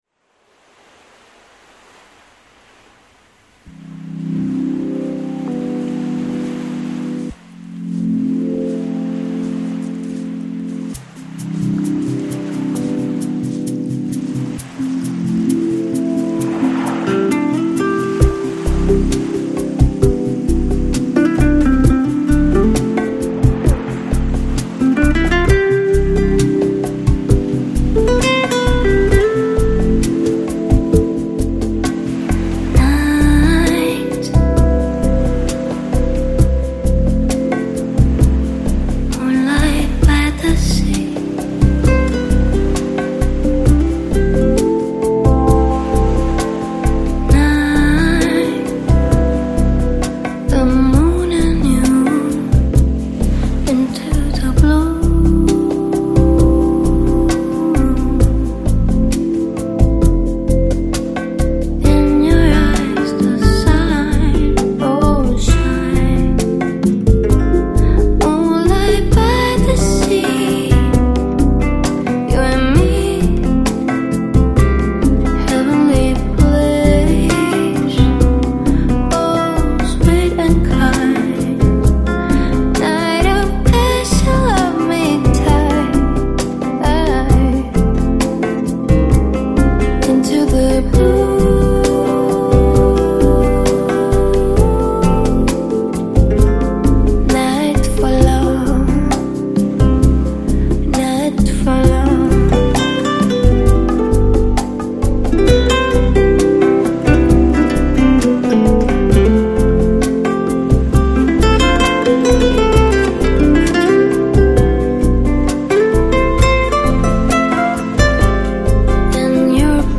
Жанр: Lo-Fi, Lounge, Chillout, Vocal